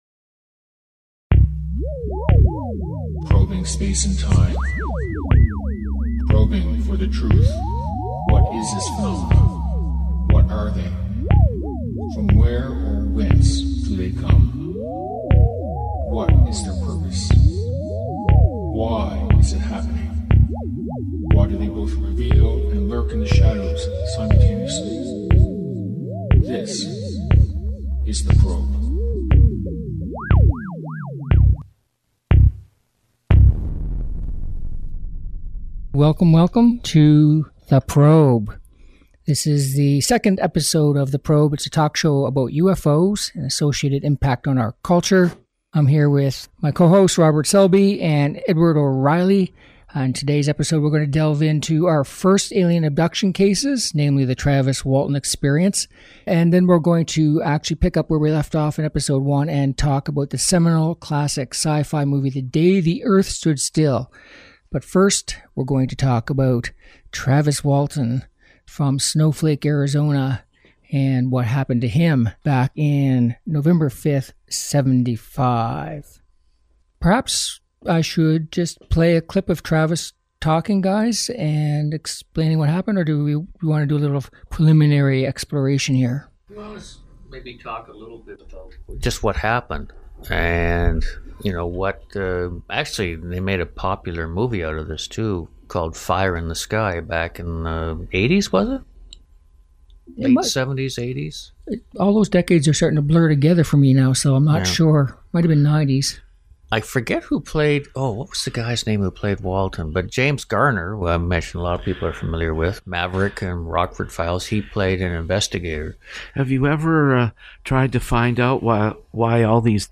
UFO Talk Show